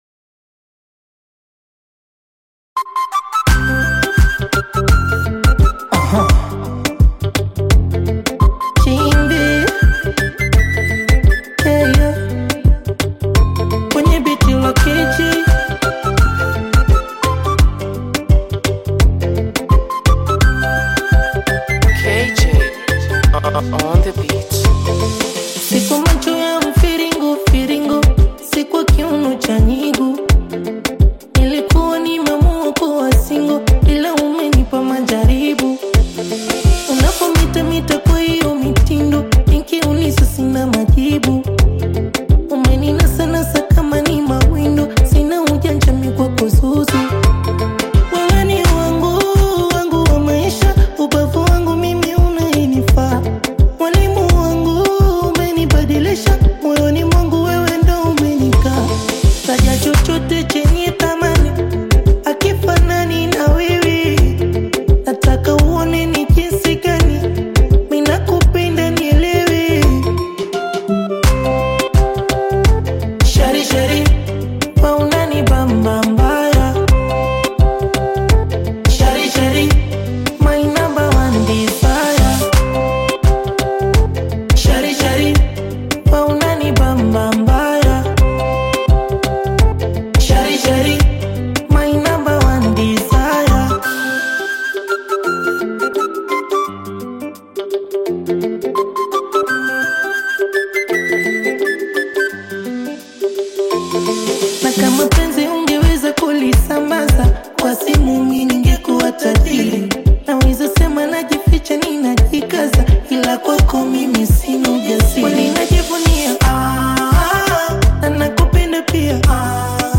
vibrant Kenyan Afro-Pop/Bongo Flava single
blends smooth vocals with upbeat Afro-inspired rhythms
energetic delivery and feel-good sound